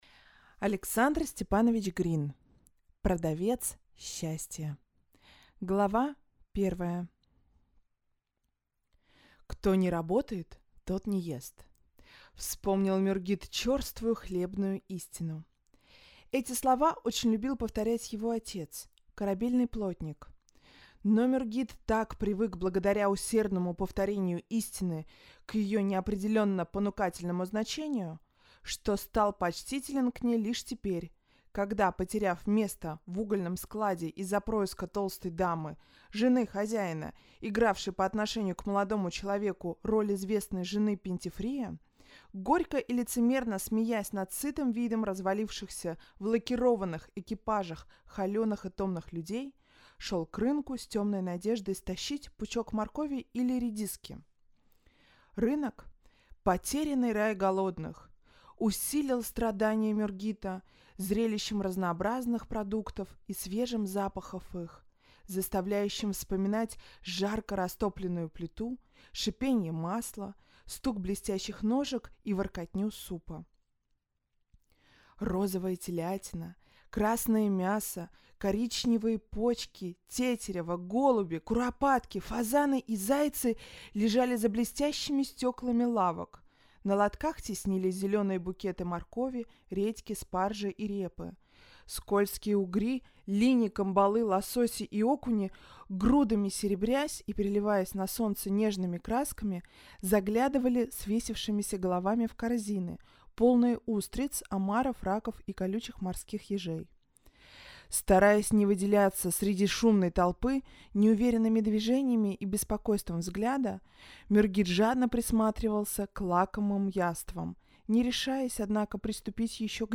Аудиокнига Продавец счастья | Библиотека аудиокниг